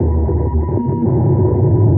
Real Sonar
sonarPingSuitVeryCloseShuttle2.ogg